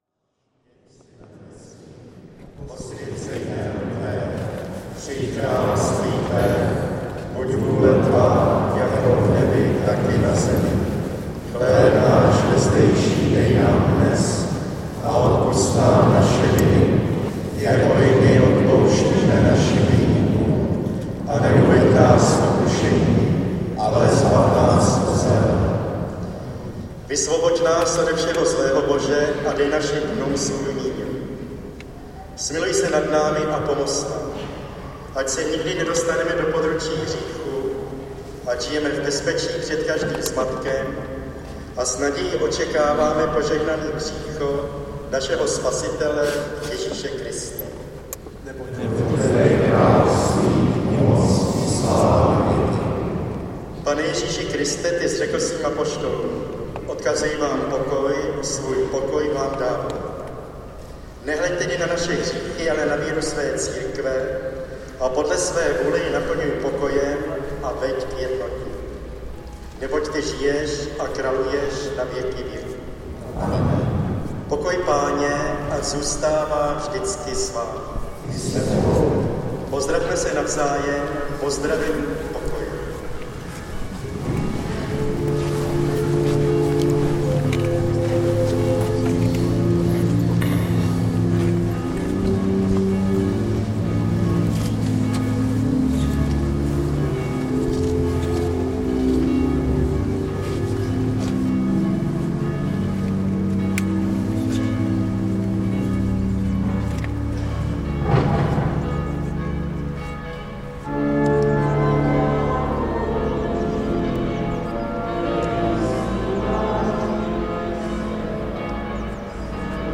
Funeral in the Saint Markéta Church | Zvuky Prahy / Sounds of Prague
field recordings, sound art, radio, sound walks
Benediktinský klášter na zasněženém Břevnově. Před vstupní branou bručí černé vládní limuziny protože šoféři nechali kvůli topení zapnuté motory.
Tentokrát to nejsou motocykly, ale řetězová pila někde ve svahu napravo nad klášterem.
Venku v koruně stromu stylově zpíval havran. Před kostelem šumělo auto s televizním přenosovým satelitem na střeše a kostel byl plný truchlících: mezi nimi bývalých disidentů, konfidentů, novinářů, potent-tátů.